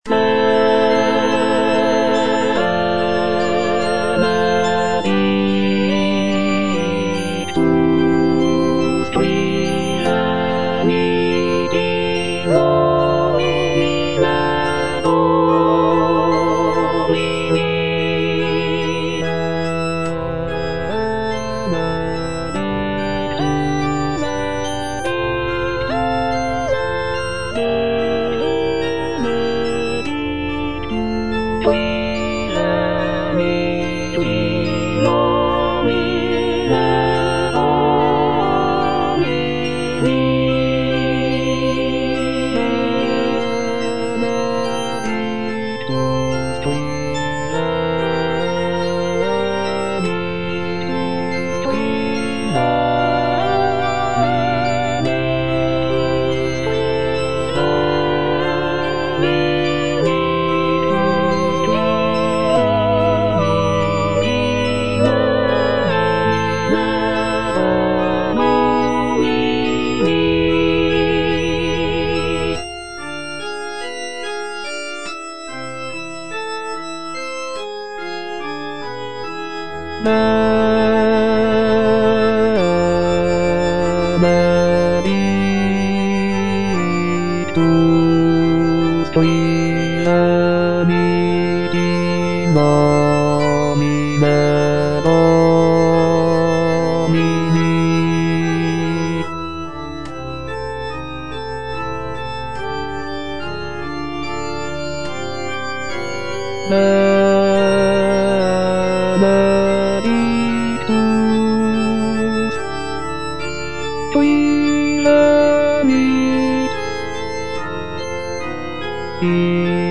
Benedictus - Bass (Voice with metronome)